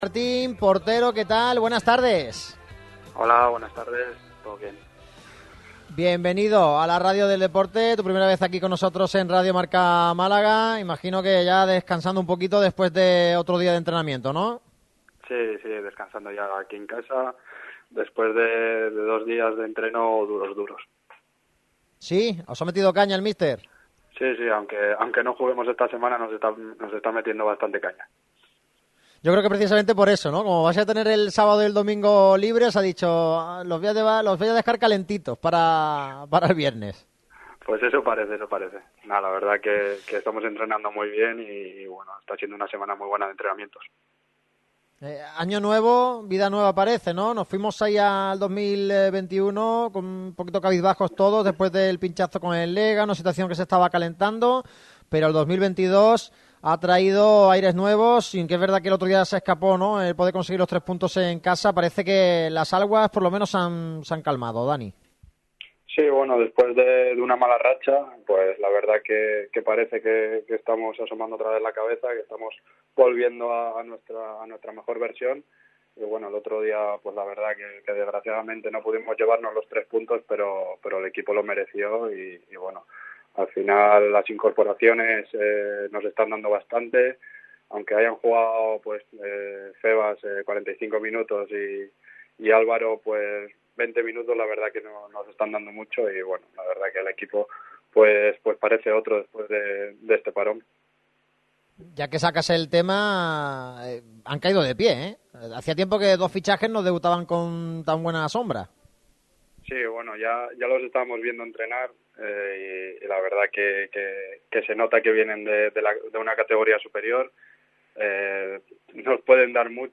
El portero titular hoy por hoy del Málaga CF, Dani Martín, ha pasado por el micrófono rojo de Radio MARCA Málaga para analizar su situación personal y la que atraviesa el club. No tuvo tapujos en hablar de ningún tema en concreto, y mostró una gran calma a la hora de tratar las críticas tras el encuentro ante el Sporting.